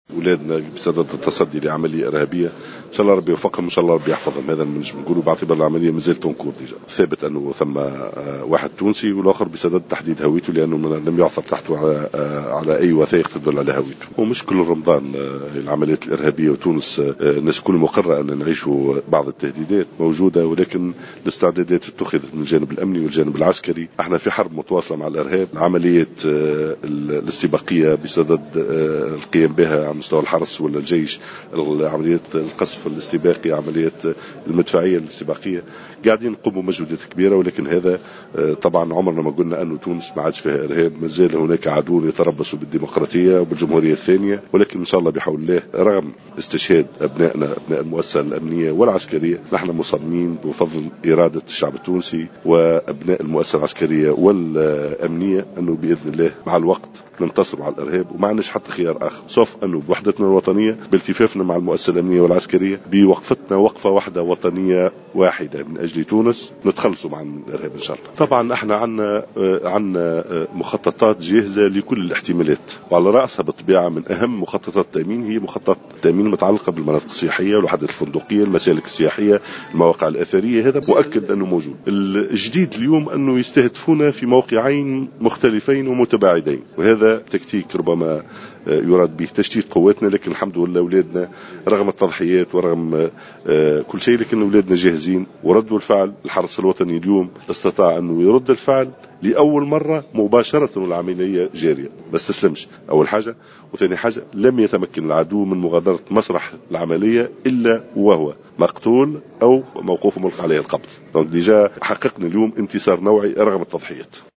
وأضاف الغرسلي على هامش اشرافه على حفل تخرج دفعة جديدة من تلاميذ المدرسة الوطنية لتكوين مفتشي الشرطة بسوسة، أن سقوط شهداء من الأمنيين والعسكريين لن يثني هاتين المؤسستين عن مواصلة التصدي للإرهاب.